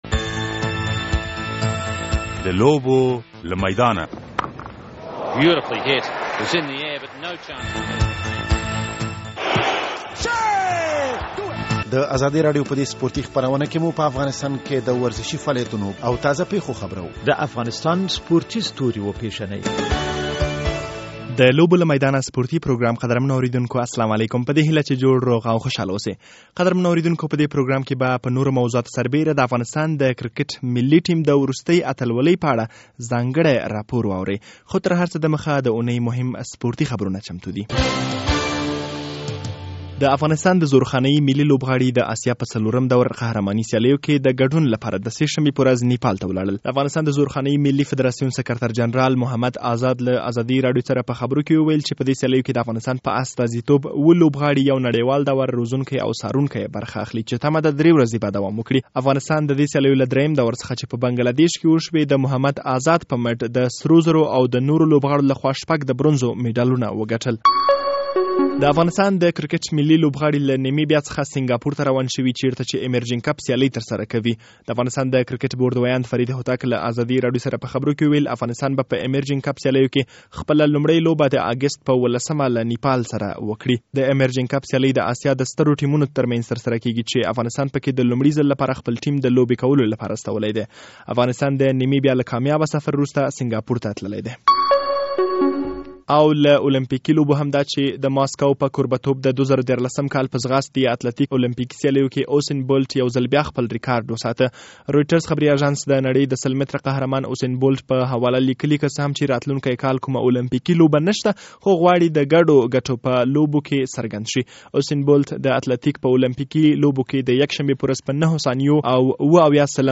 په دې پروګرام کې به د افغانستان د کرکټ د ملي ټیم د وروستۍ بریا او راتلونکې لوبې په اړه ځانګړی راپور واورئ او د فوټبال د غوره لیګ د پیلیدو په اړه هم یوه مرکه اوریدلئ شئ.